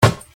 nt snare-kick 1.wav